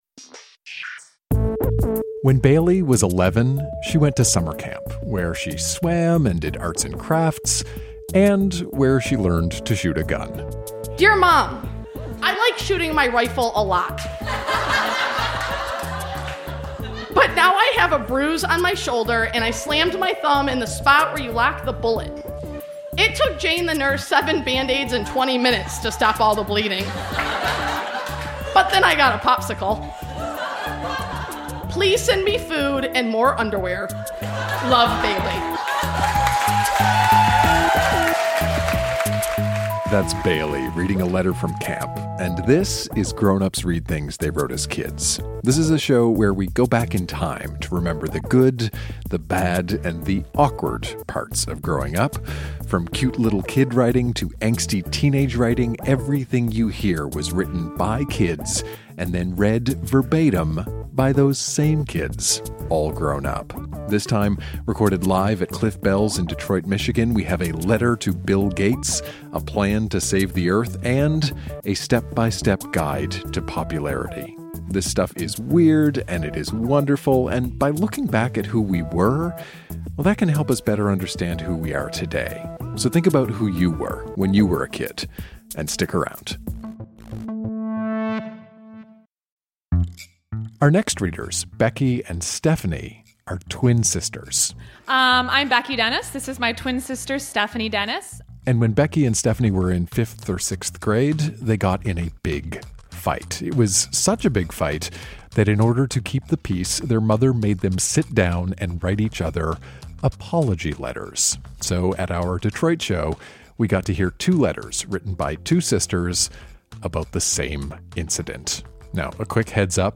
Firearm accidents, a speech for Bill Gates, and a step-by-step guide to popularity. Recorded live at Cliff Bell's in Detroit, MI.